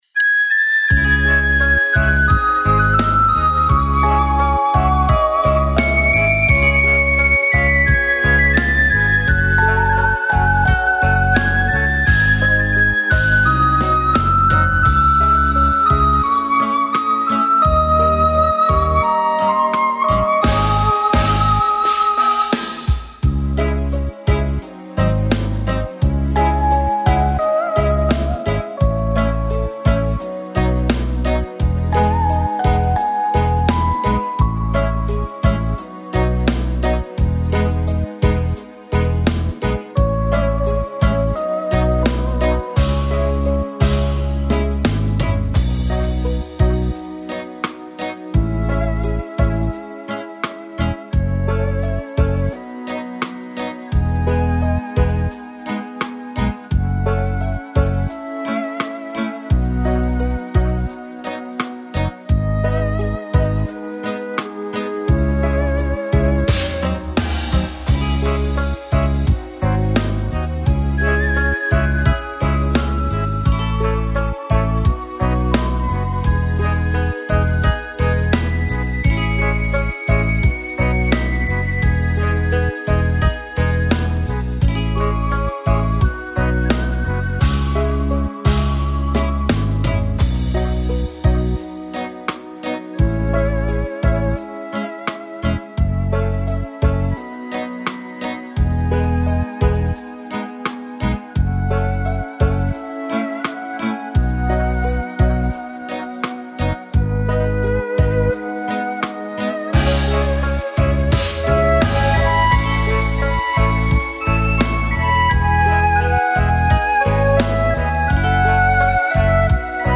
Tone Nữ (C#m)
•   Beat  01.